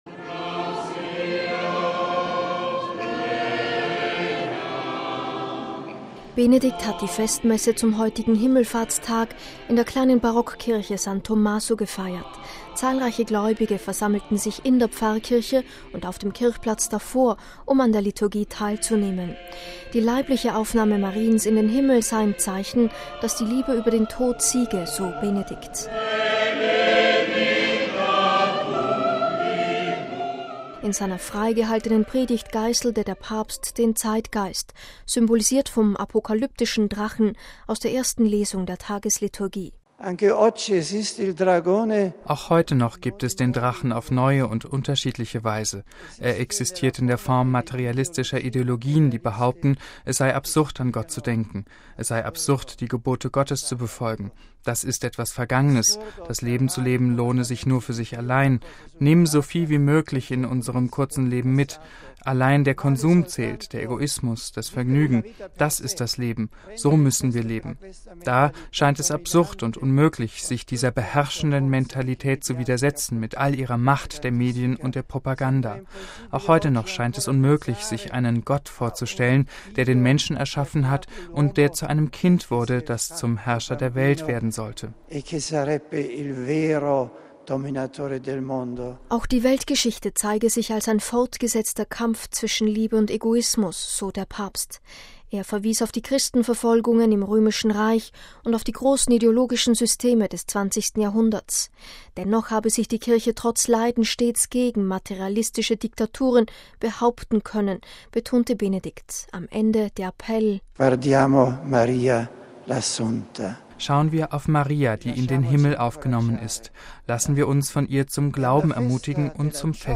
Benedikt hat die Festmesse zum heutigen Himmelfahrtsmesse in der kleinen Barockkirche San Tommaso gefeiert. Zahlreiche Gläubige versammelten sich in der Pfarrkirche und auf dem Kirchplatz davor, um an der Liturgie teilzunehmen.